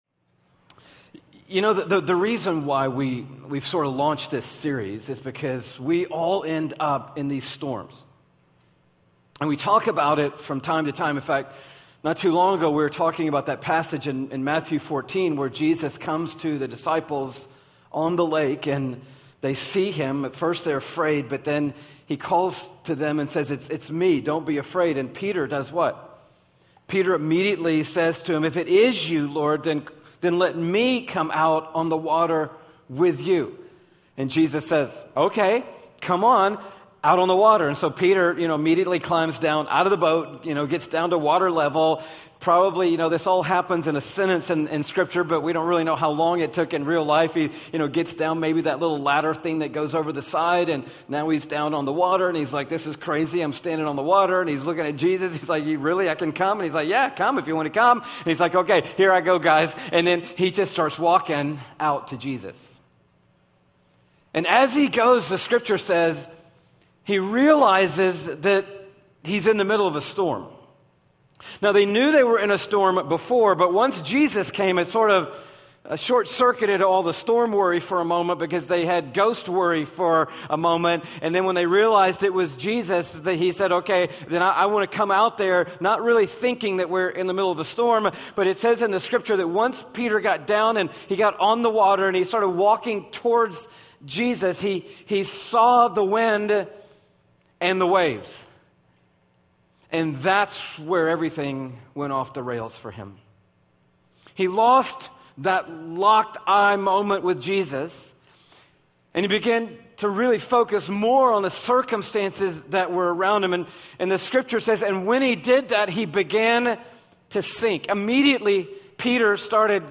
Messages, Church, Sermons, Christianity, Louiegiglio, Religion & Spirituality, Passionconferences, Passion, Passioncitychurch